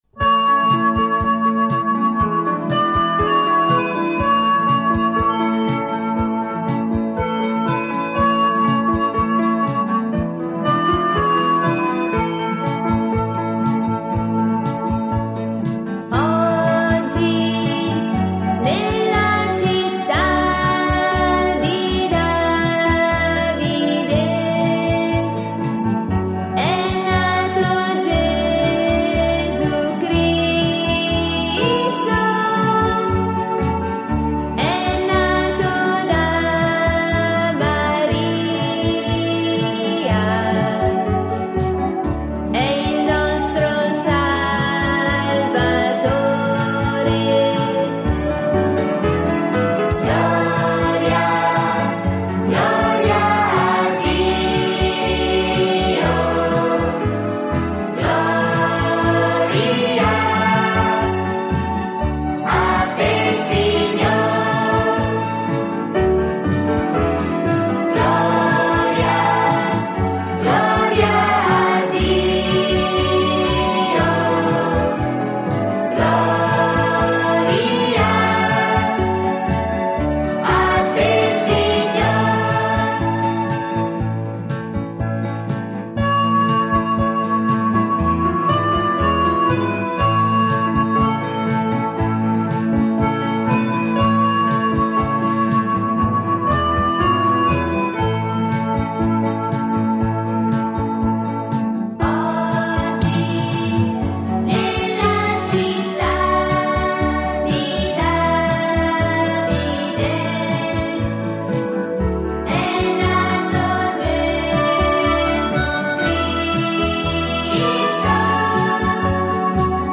Canto per Rosario e Parola di Dio: Oggi nella città di Davide